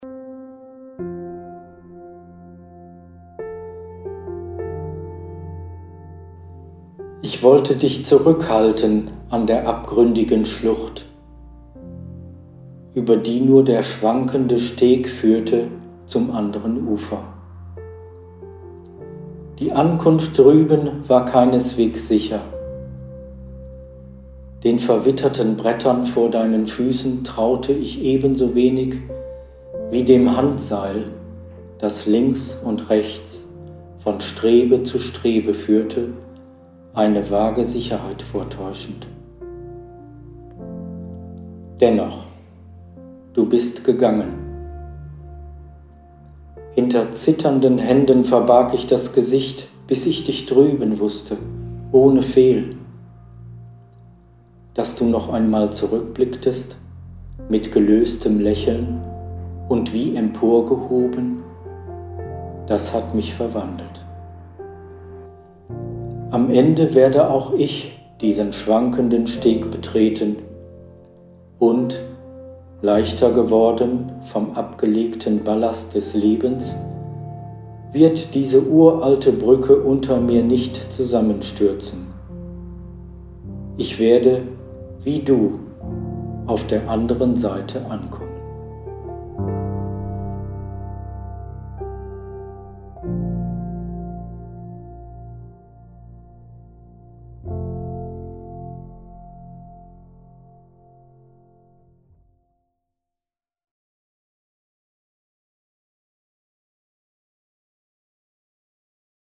Gesprochene Texte zum Thema Trauer